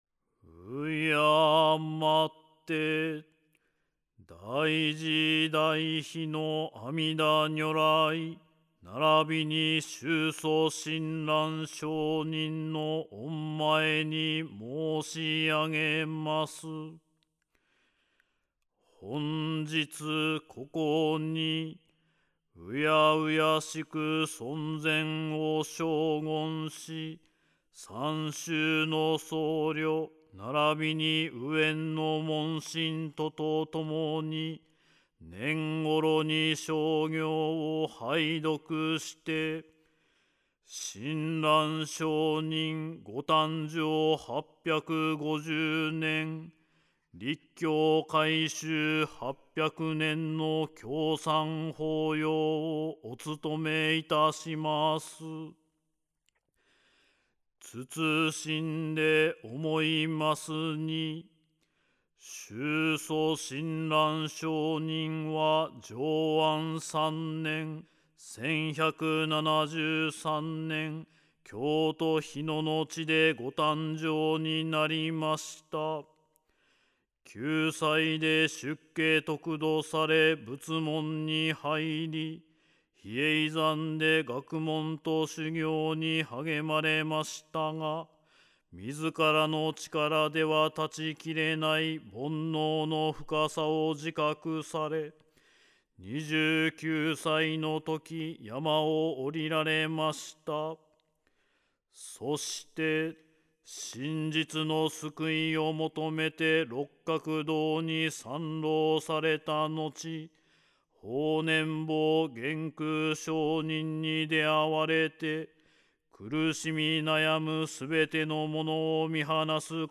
宗祖親鸞聖人によってお念仏のみ教えの真意が開顕された浄土真宗の根本聖典｢顕浄土真実教行証文類｣（教行信証）からすべて御文を選定し、伝統的な声明と大衆唱和の両面を兼ね備えたものとなっています。
また、多くの作法が高音からはじまるものでありますが、聴く・唱えるという視点も重視して、より唱和しやすいものとなるよう、全体的に中低音から次第に高音域に至る採譜が施されています。